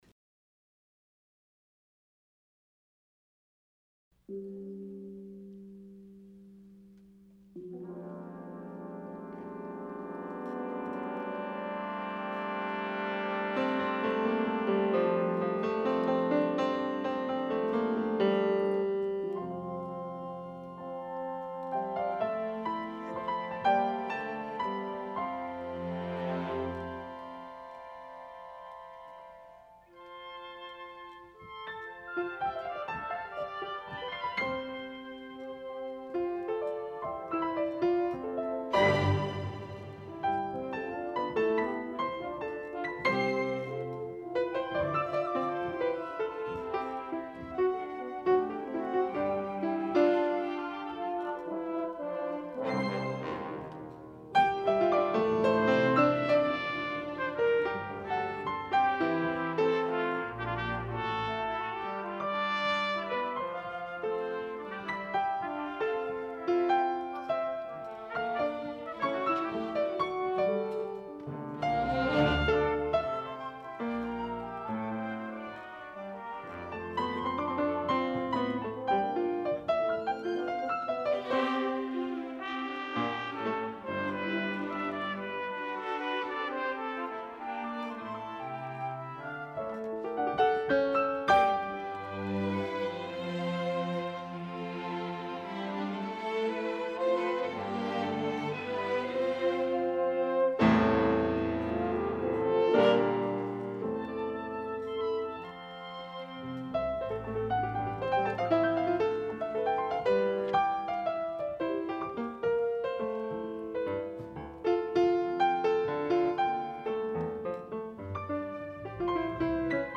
orchestral work
piano solo and orchestra, based on early music